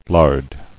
[lard]